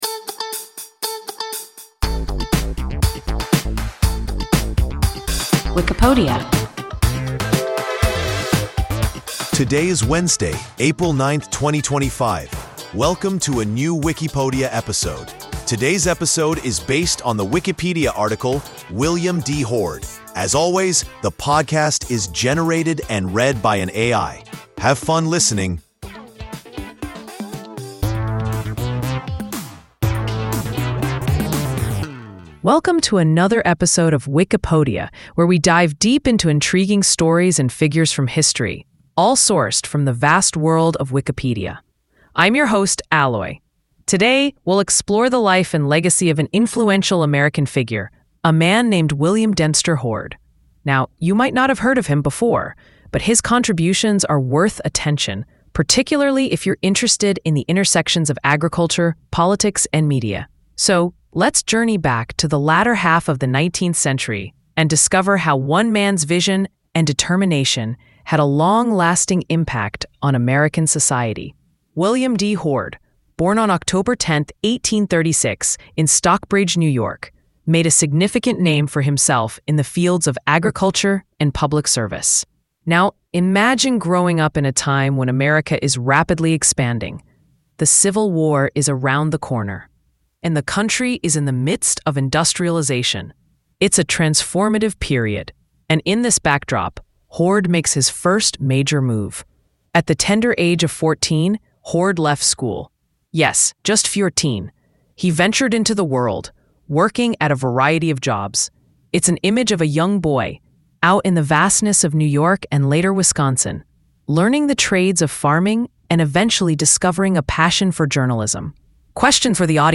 William D. Hoard – WIKIPODIA – ein KI Podcast